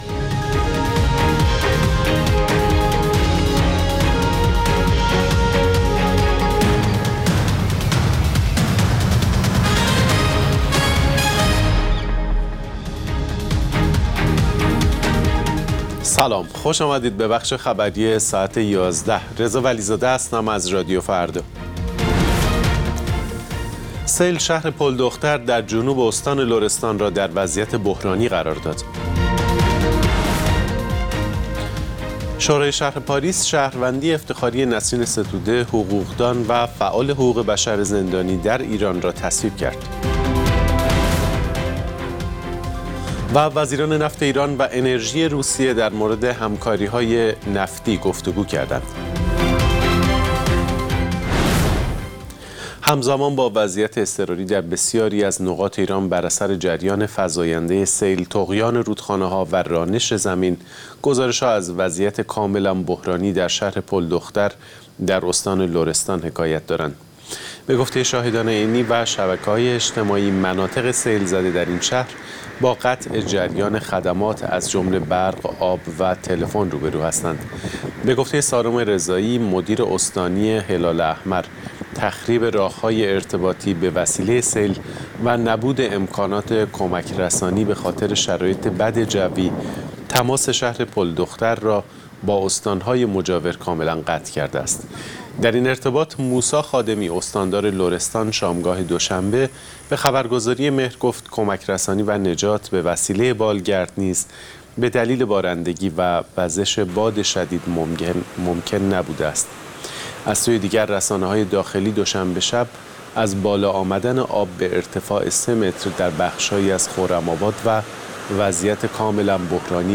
اخبار رادیو فردا، ساعت ۱۱:۰۰